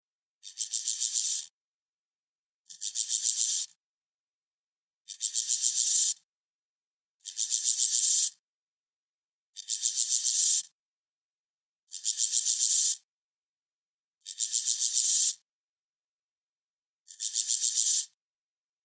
Piennarheinäsirkan kutsulaulu